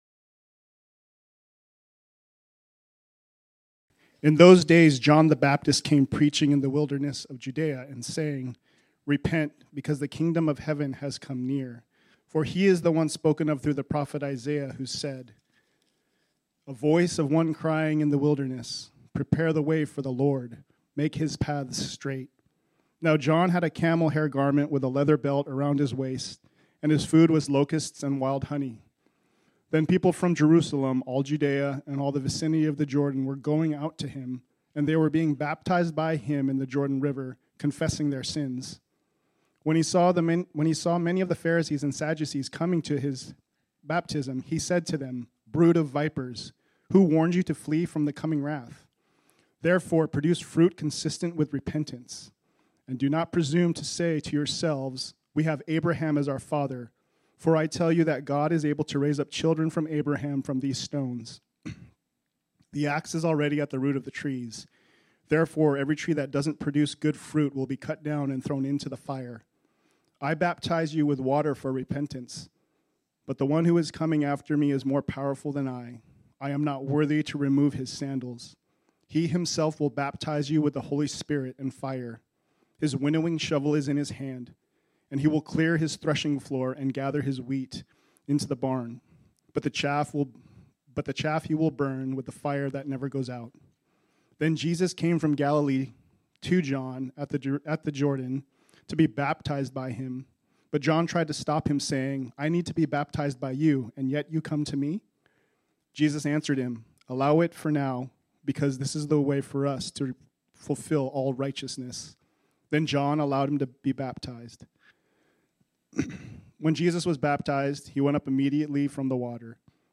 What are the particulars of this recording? This sermon was originally preached on Sunday, January 7, 2024.